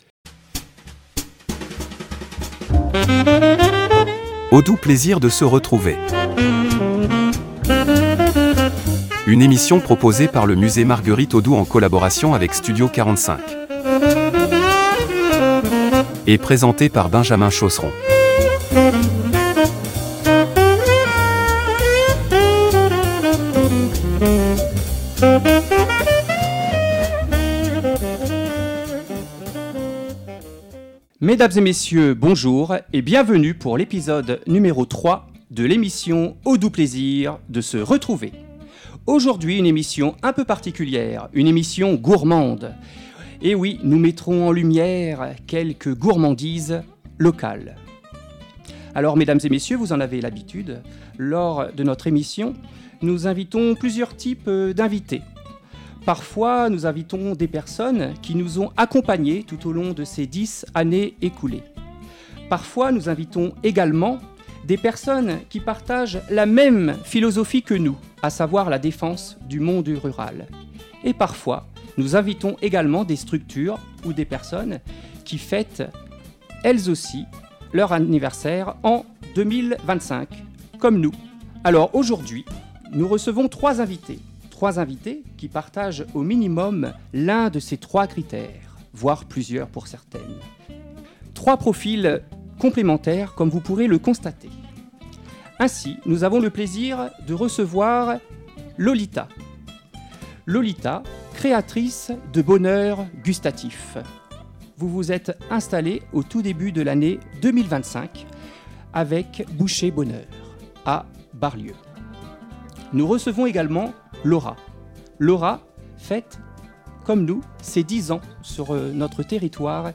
Une émission conviviale, gourmande et pleine de découvertes locales, à savourer en podcast sur Studio 45 !